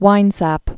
(wīnsăp)